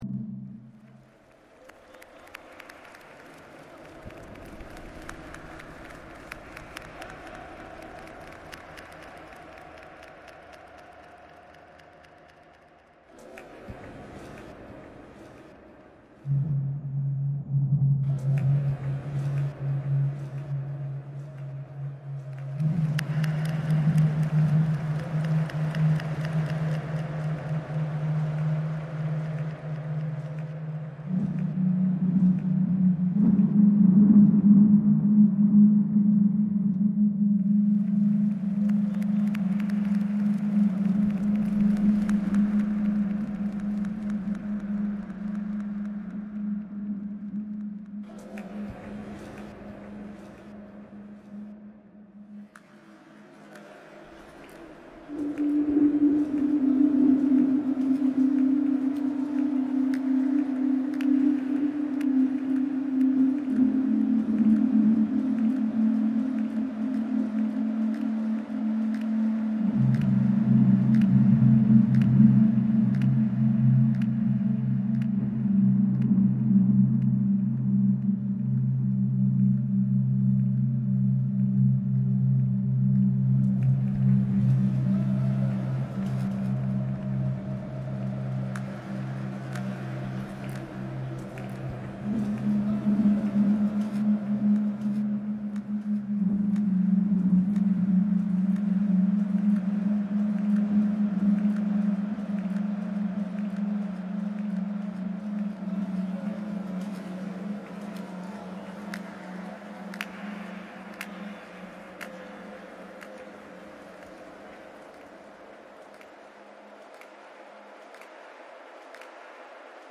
chant reimagined